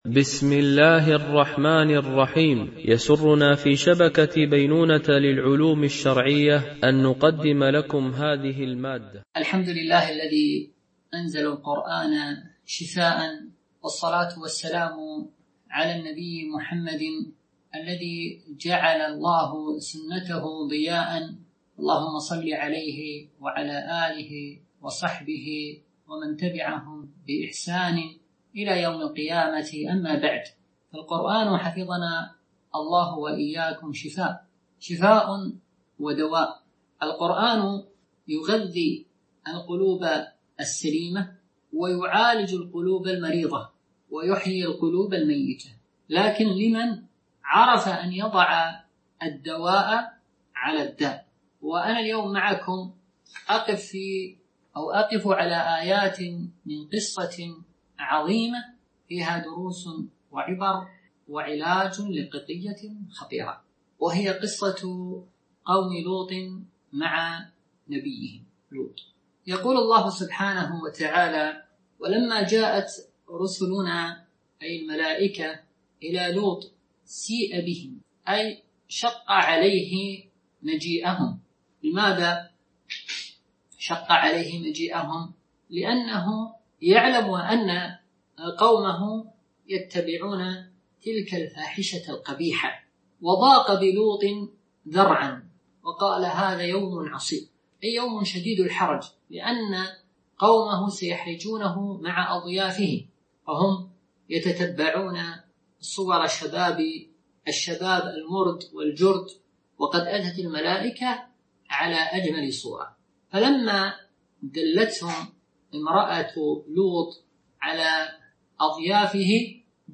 سلسلة محاضرات نسائم إيمانية وقيم أخلاقية
MP3 Mono 22kHz 32Kbps (CBR)